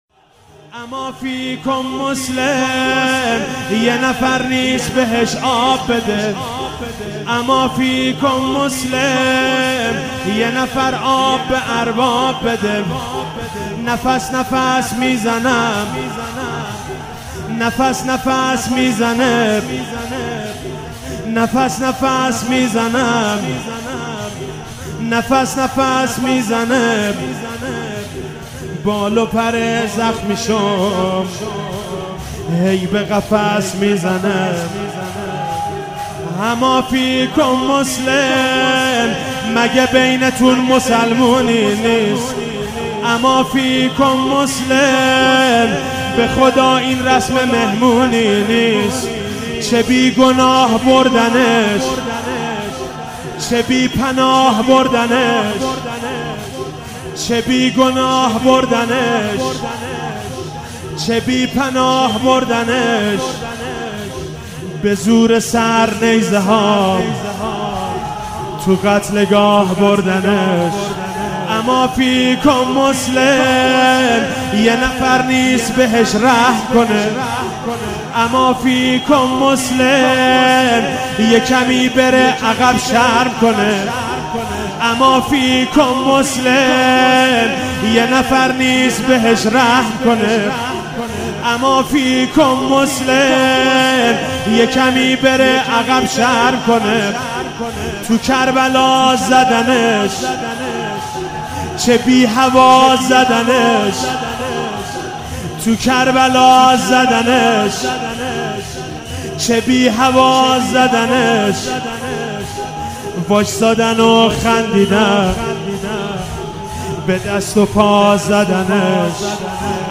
شب 21 ماه مبارک رمضان 96(قدر) - شور - اما فیکم مسلم یه نفر نیس
شور